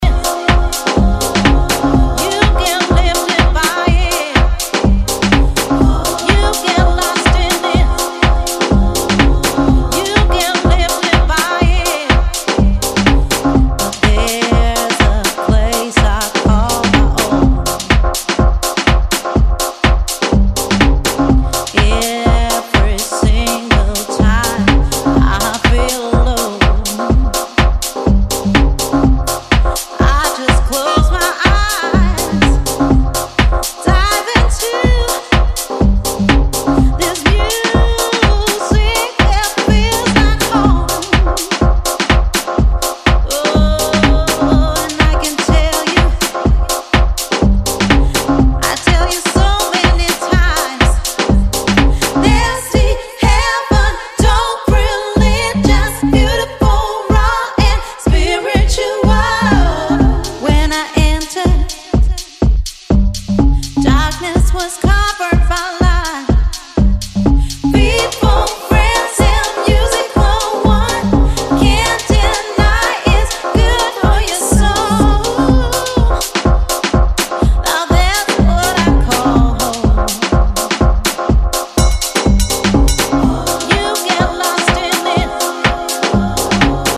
London’s cavernous Printworks
piano fuelled groover, tough, melodic